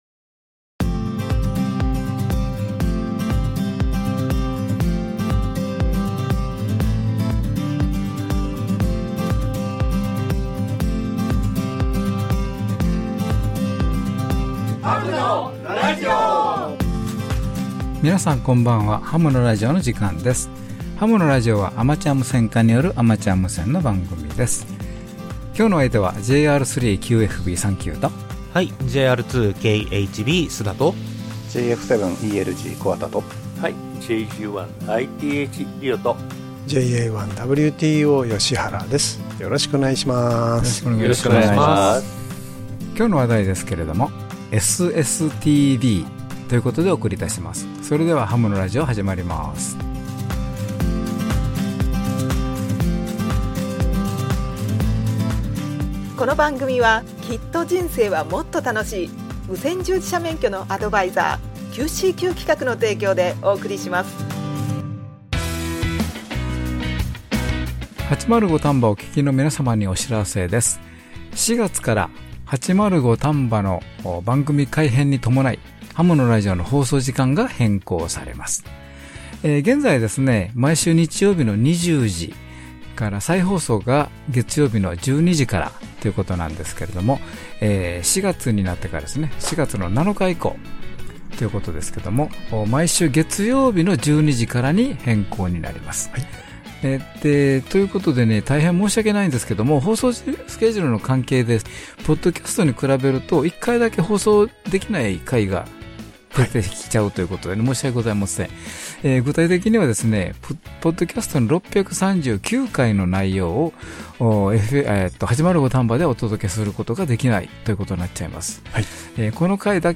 アマチュア無線家によるアマチュア無線のラジオ番組